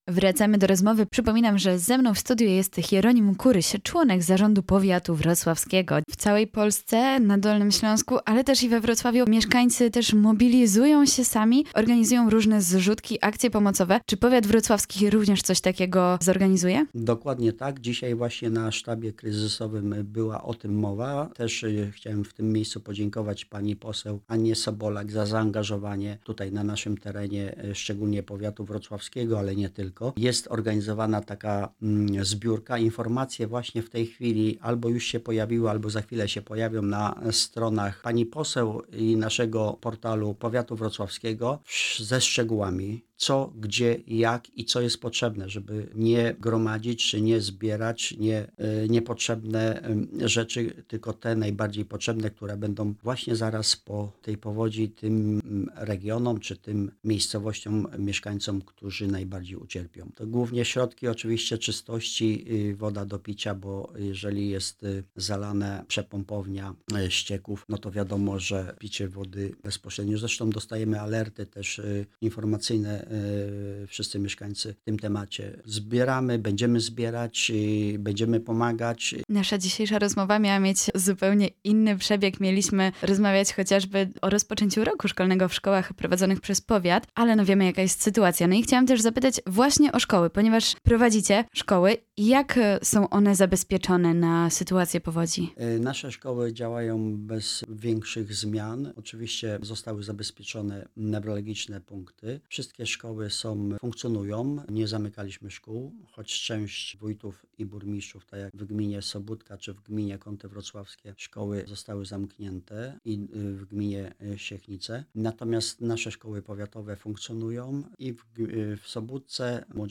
Hieronim Kuryś, czyli członek zarządu powiatu wrocławskiego był w poniedziałek gościem Radia Rodzina.